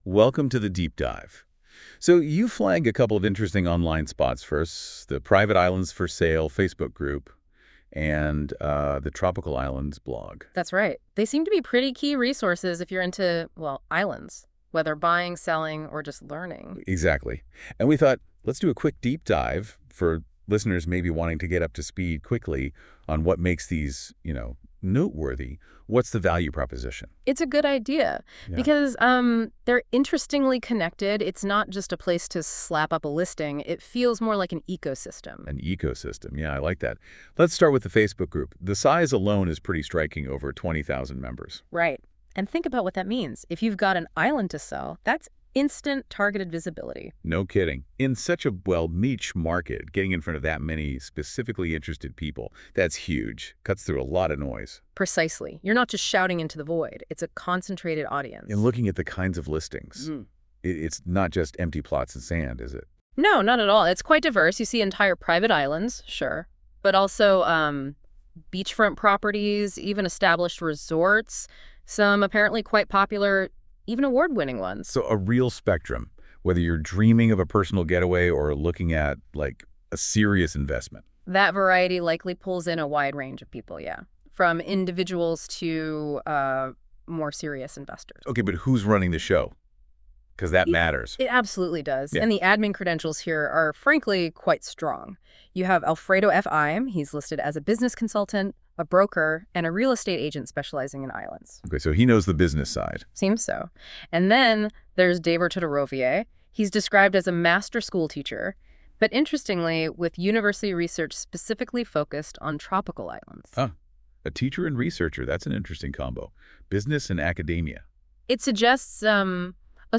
This is Gemini AI generated podcast of male and female having conversation about advantages of our group plus blog combo. It used gathered information from blog and group to create this podcast.